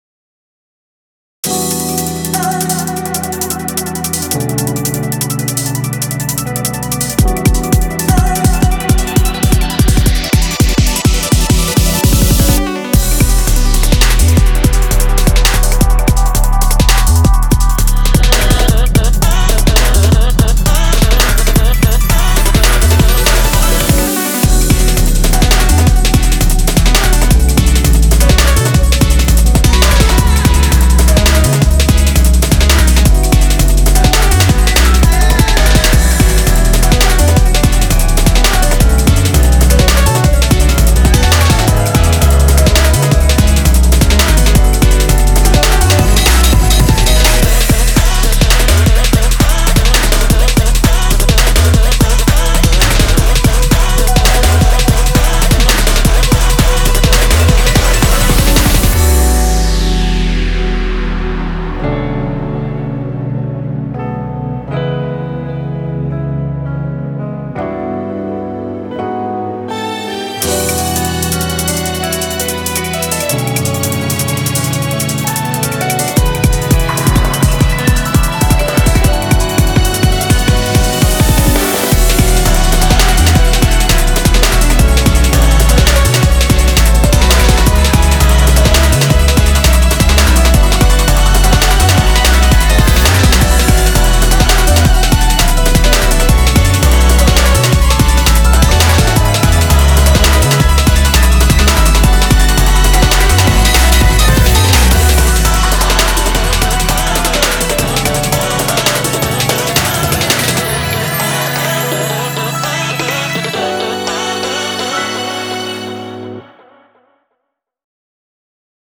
BPM84-167
Audio QualityPerfect (High Quality)
Genre: JAZZY FOOTWORK JUNGLE